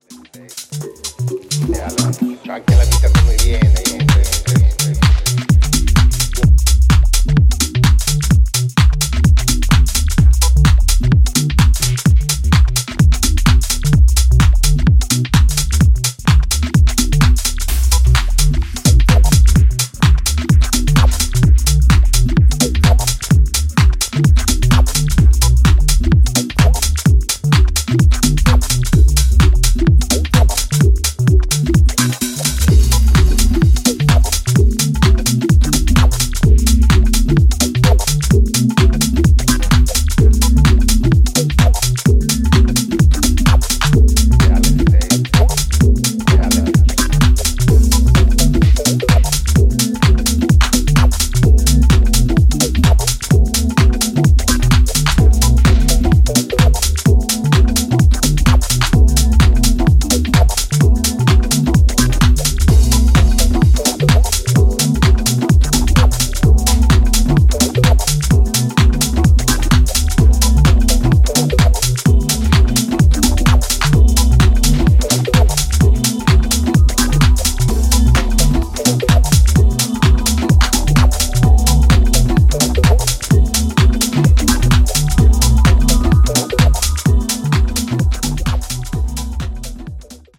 ジャンル(スタイル) TECH HOUSE